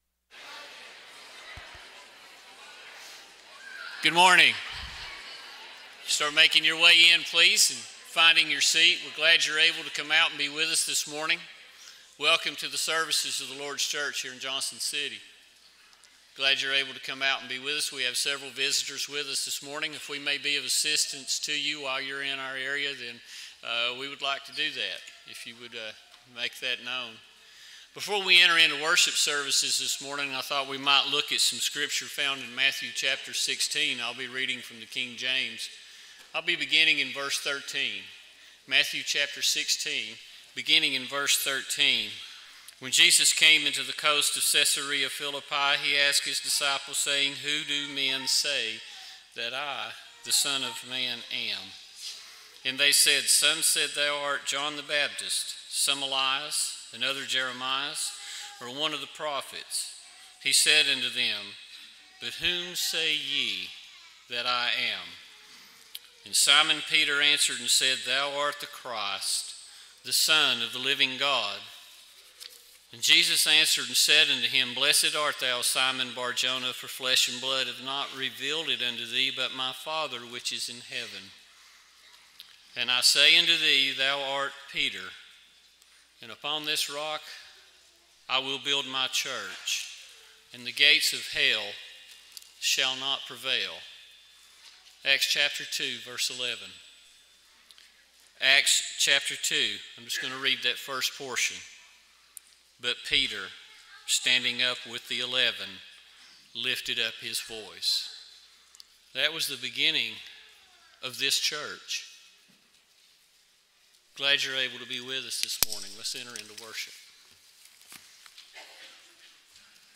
Luke 24:28-29, English Standard Version Series: Sunday AM Service